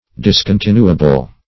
Discontinuable \Dis`con*tin"u*a*ble\, a. Admitting of being discontinued.